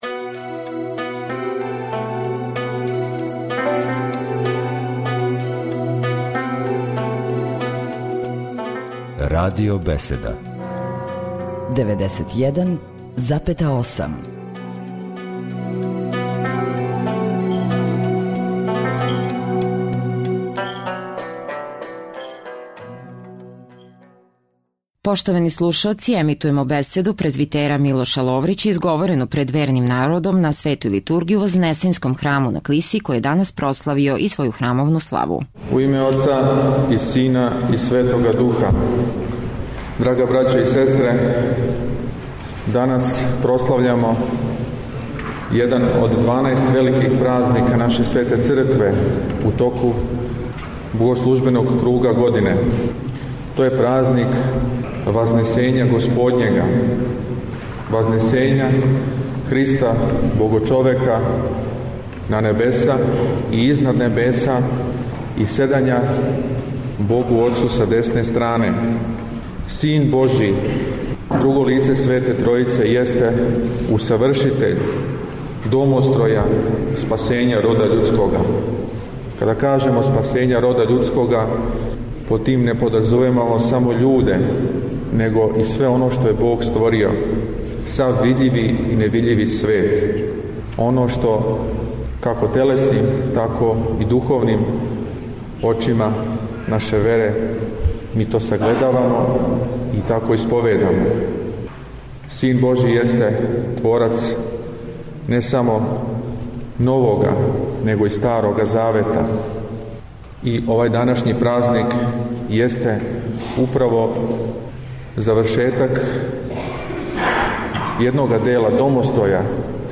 Прослава празника Вазнесења Господњег у Епархији бачкој
• Беседа